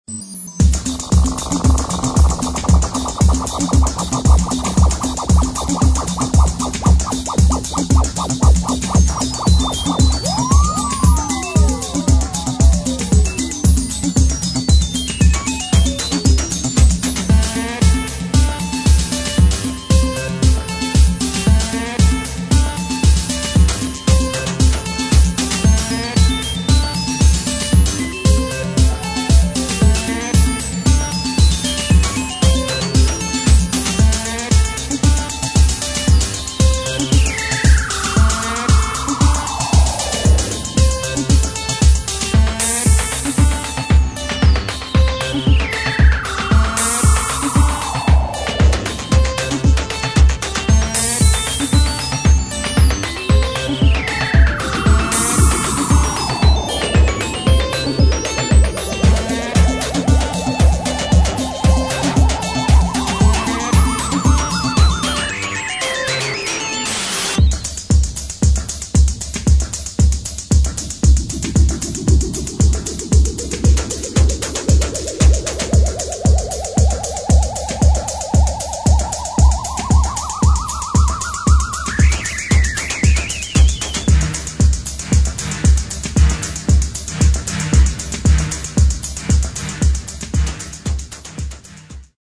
[ DEEP HOUSE / DEEP MINIMAL / COSMIC DISCO ]